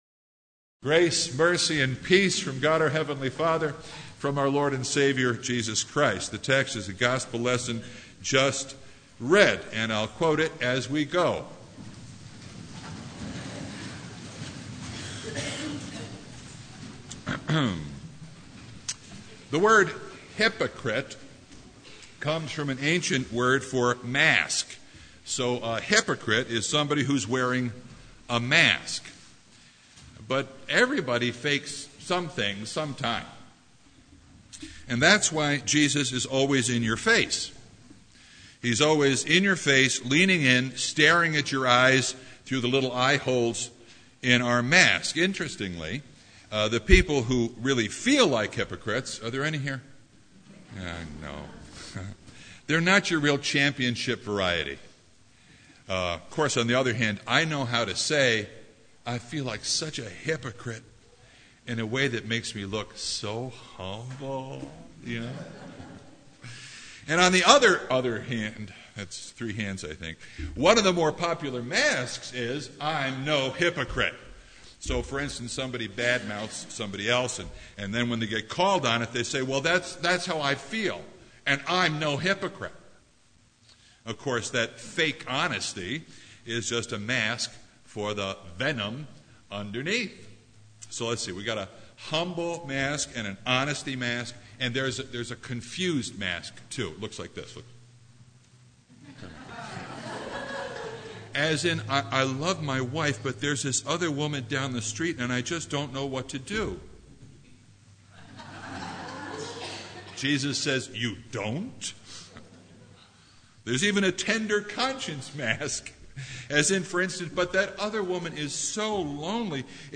Passage: Matthew 22:15-22 Service Type: Sunday
Sermon Only